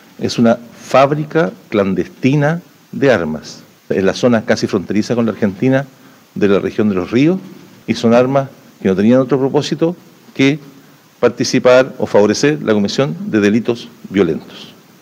Por su parte, el fiscal nacional Ángel Valencia, calificó el hallazgo como de gran relevancia porque las armas tenían como fin la comisión de delitos violentos.